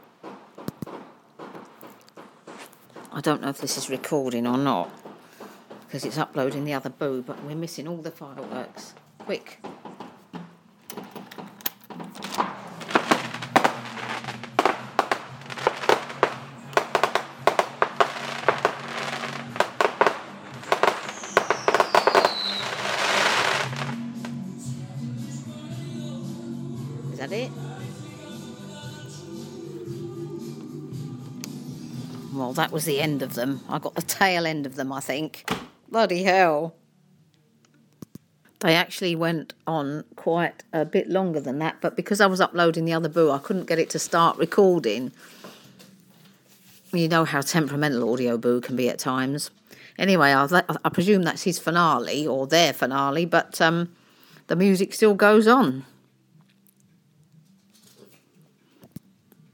Fireworks finale, the tail end